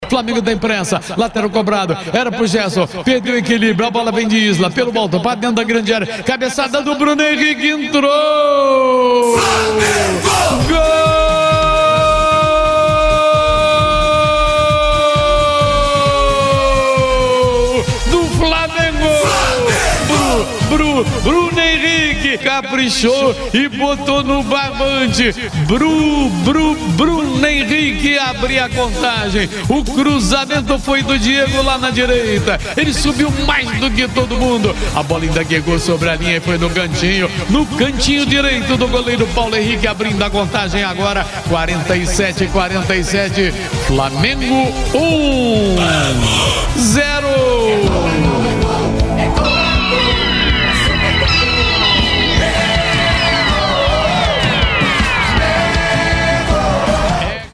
Ouça os gols da vitória do Flamengo sobre o Bangu com a narração de José Carlos Araújo